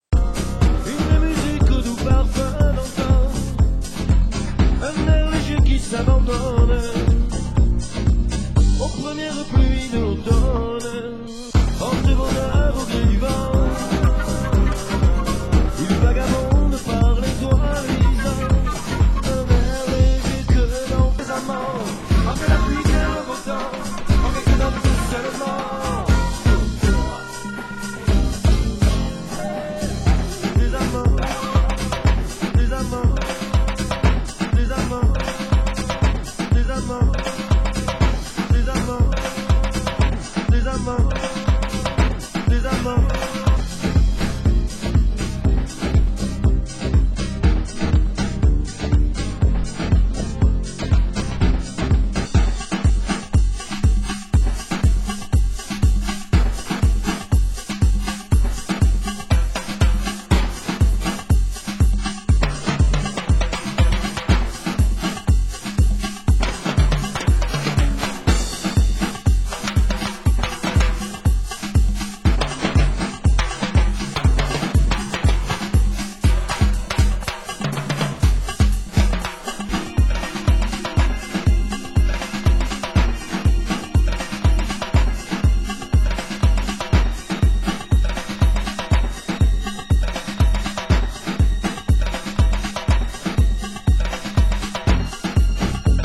Genre: Down Tempo